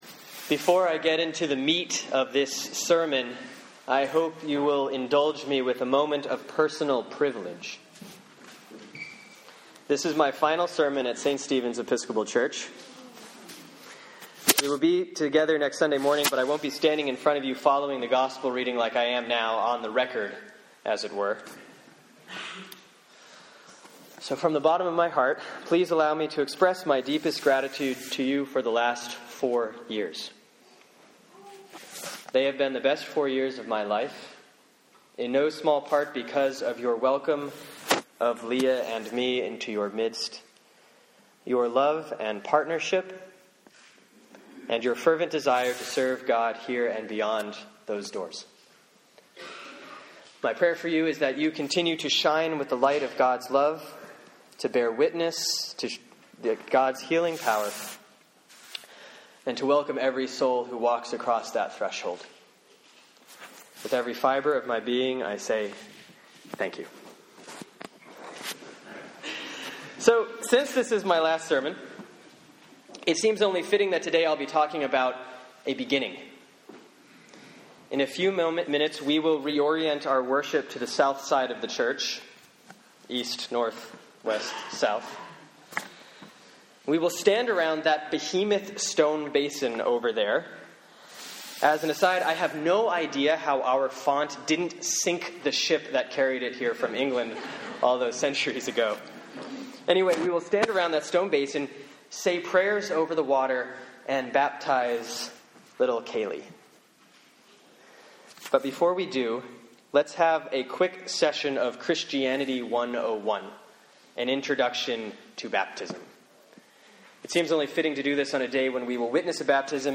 Sermon for Sunday, January 12, 2014 || Epiphany 1A || Matthew 3:13-17